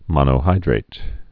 (mŏnō-hīdrāt)